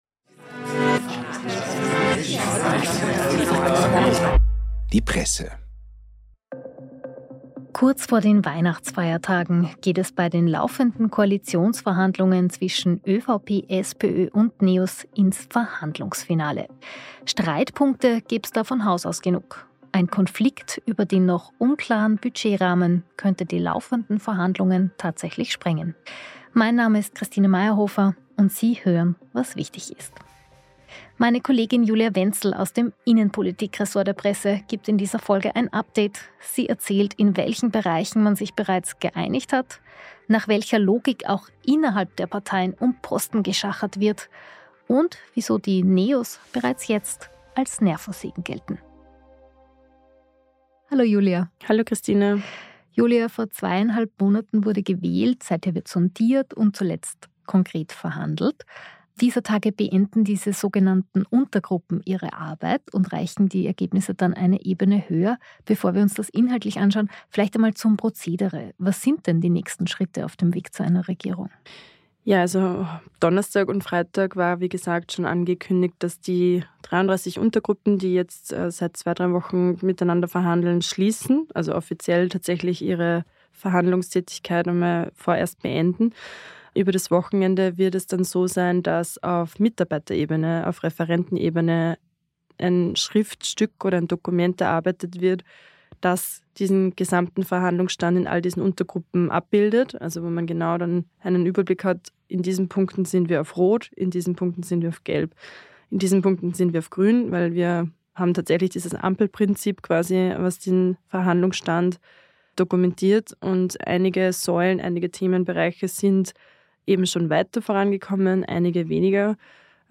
Der Nachrichten-Podcast der Tageszeitung "Die Presse" erscheint wochentags Montag bis Freitag um 18 Uhr und zu besonderen Anlässen wie an Wahlsonntagen auch zwischendurch. Die Redaktion der "Presse" sagt, was wichtig ist und nimmt Sie mit auf Recherche und zu spannenden Gesprächspartnern.